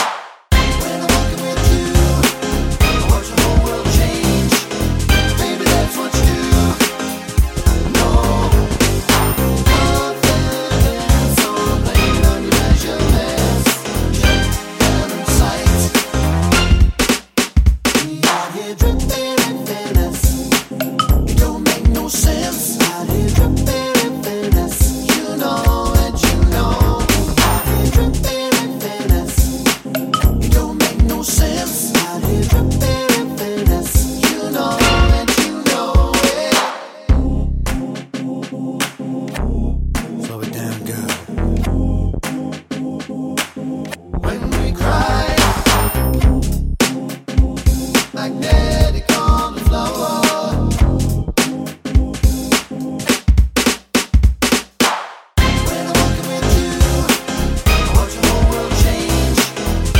Remix Pop (2010s) 3:38 Buy £1.50